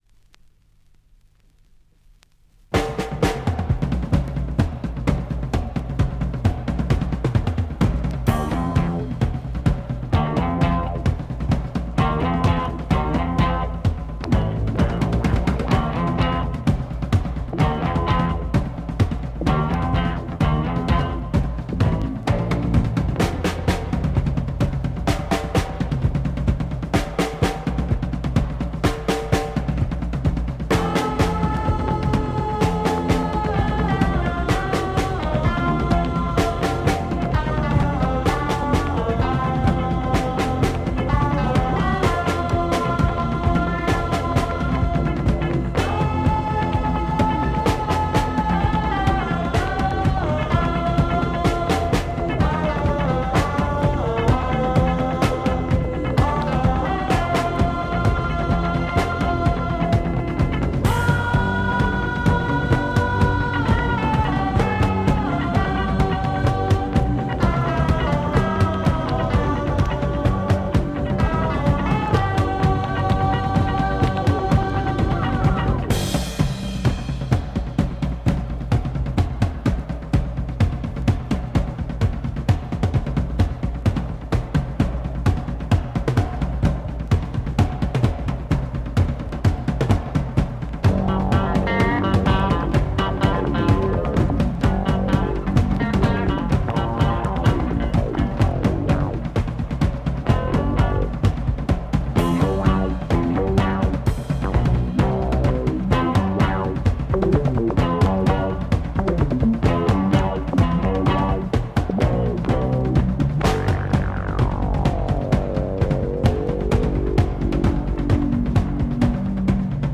Drum break B-Boy funk psych Portuguese pic!